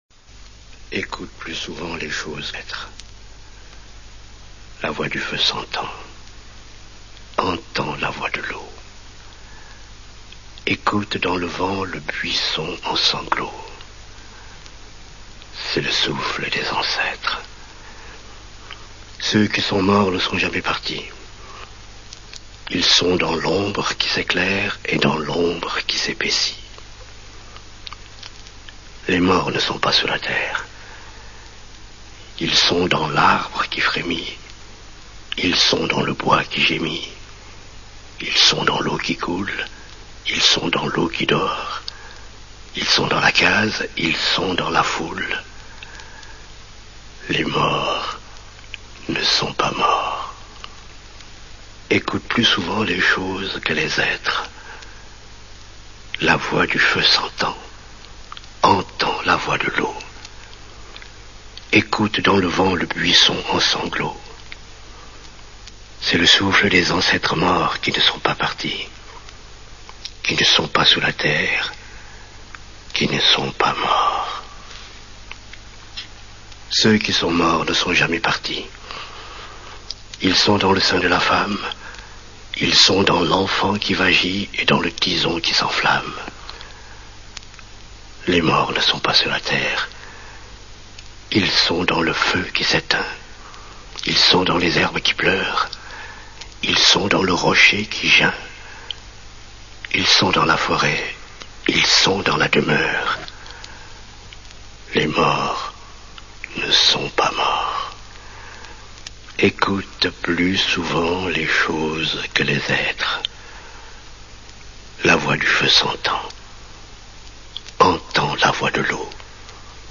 Il est dit ici par Birago Diop lui-même :